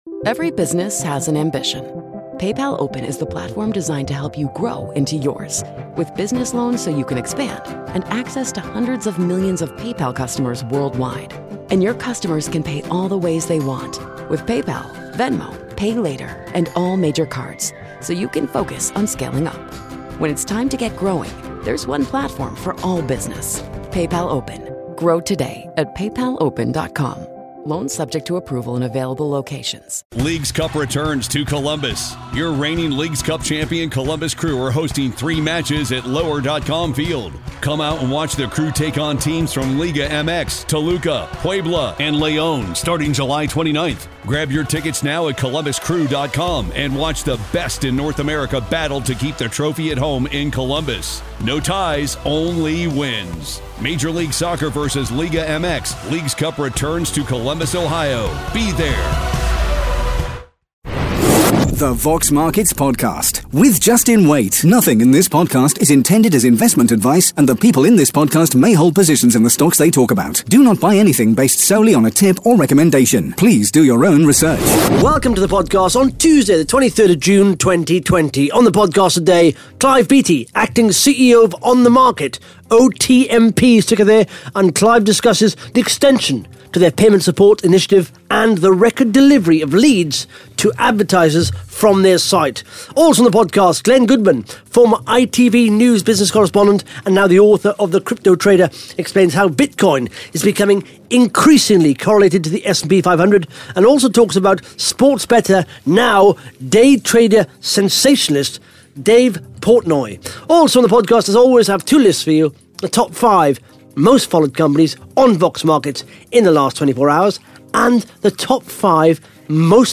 (Interview starts at 13 minutes 38 seconds) Plus the Top 5 Most Followed Companies & the Top 5 Most Liked RNS’s on Vox Markets in the last 24 hours.